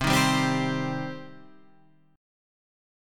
Cm chord {8 6 5 5 8 8} chord